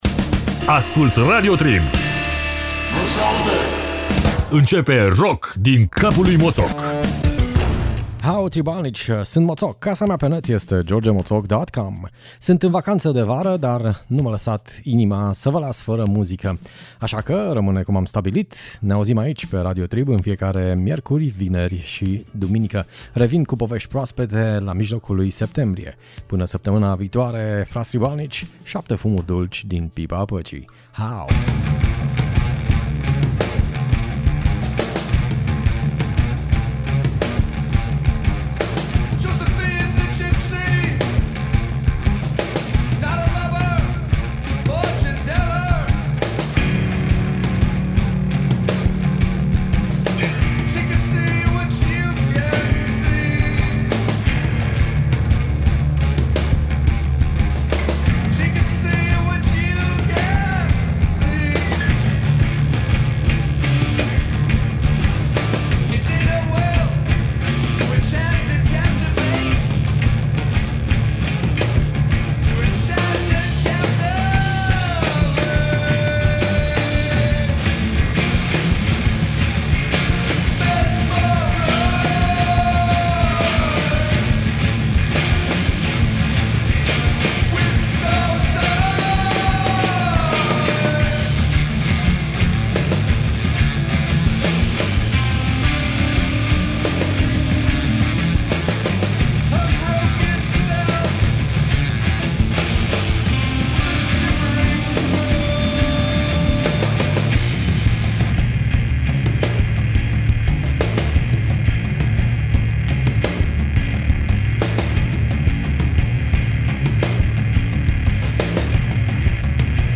Muzica buna de dansat la nunta.